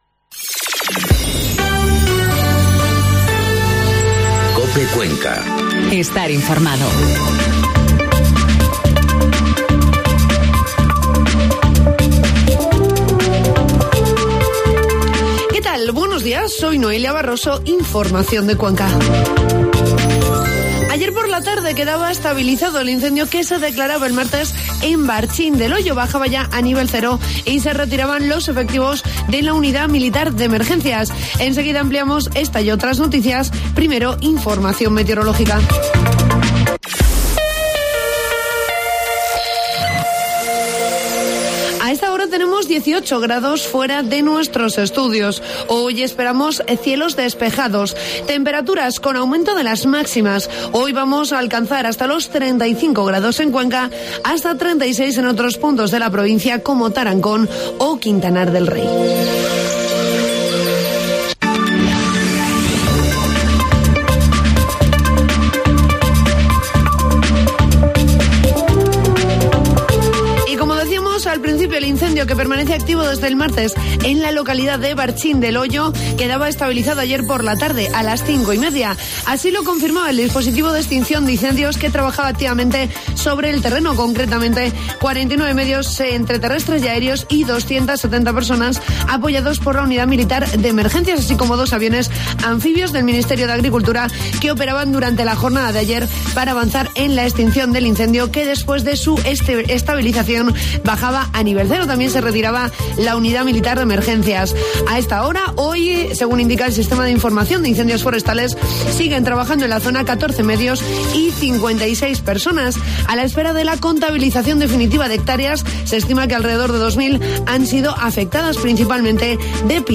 Informativo matinal COPE 2 de agosto